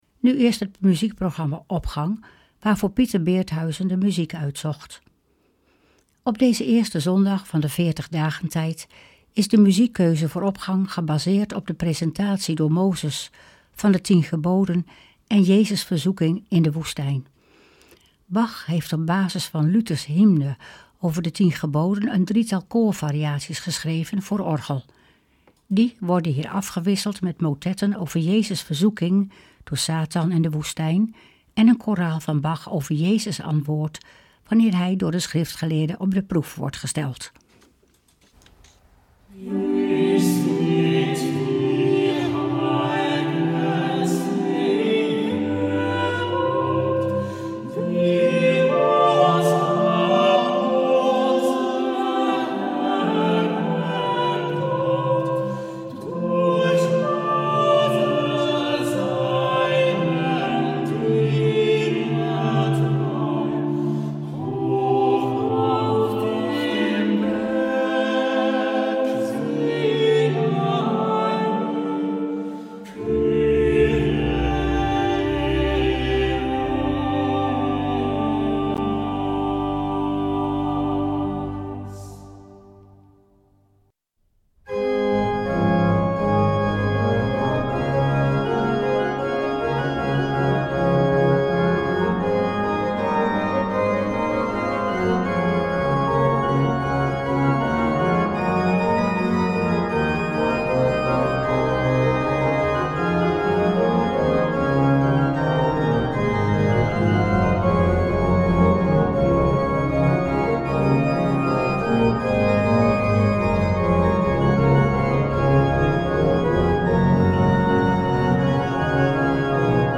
Opening van deze zondag met muziek, rechtstreeks vanuit onze studio.
koraalvariaties geschreven voor orgel
motetten